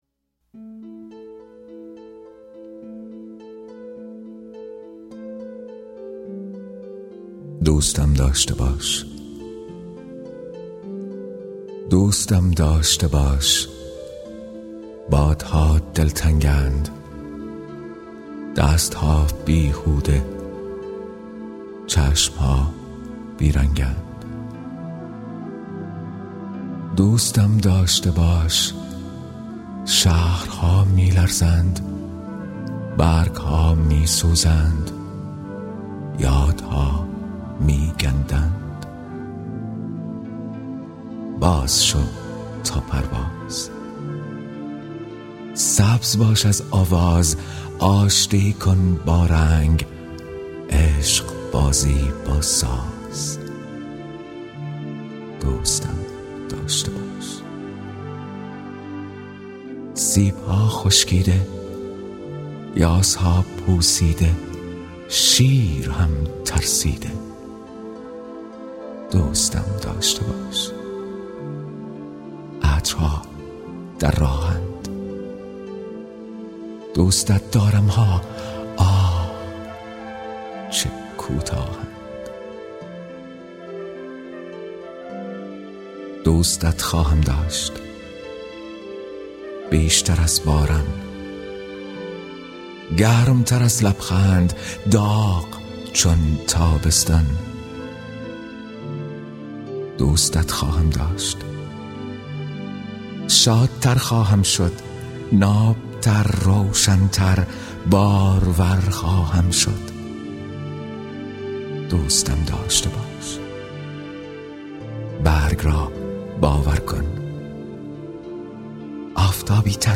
دانلود دکلمه دوستم داشته باش با صدای شهیار قنبری
گوینده :   [شهیار قنبری]